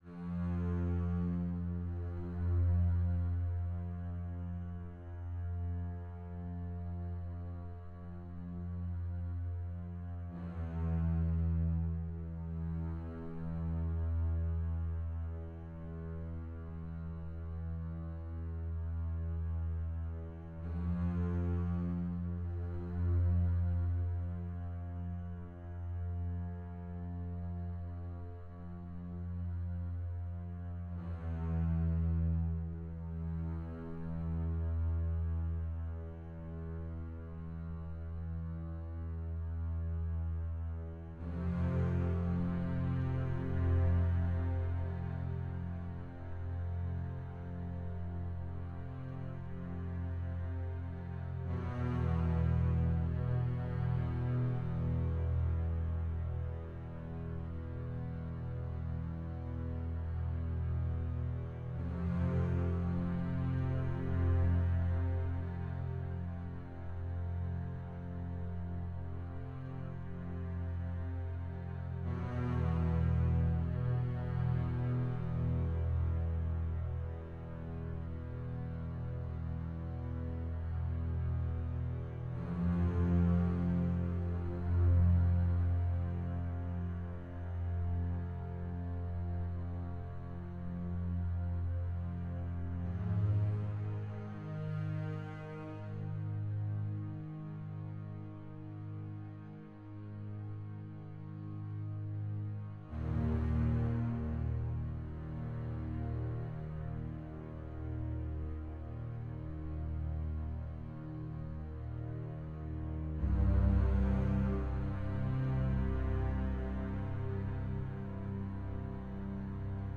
the score dances around